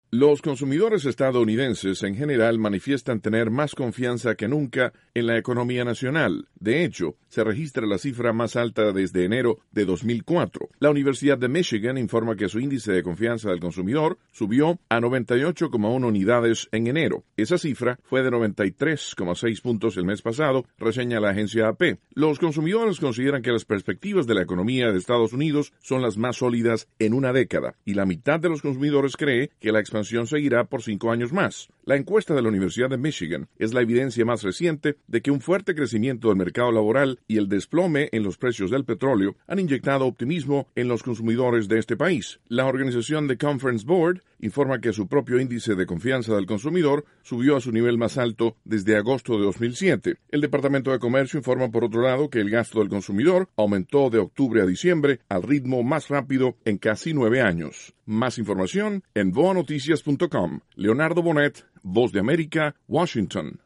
informa desde Washington